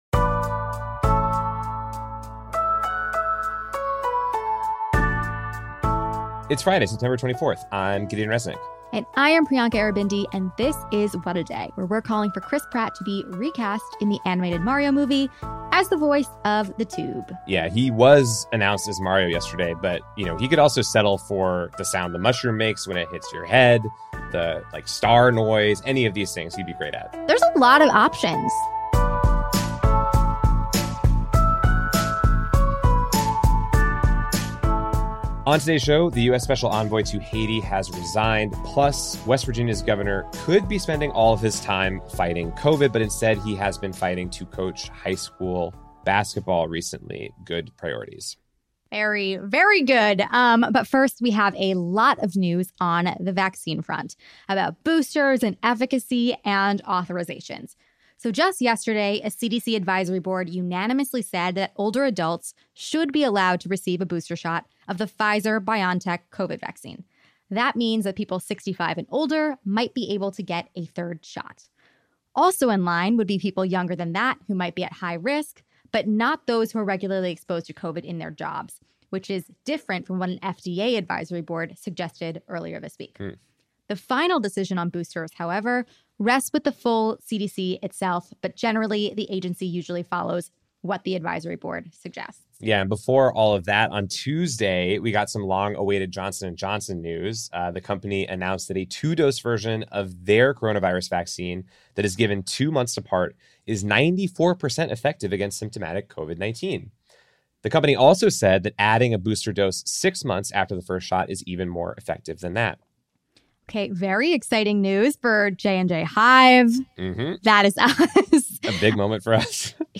Dr. Abdul El-Sayed, epidemiologist and the host of America Dissected, joins us to breakdown vaccine news.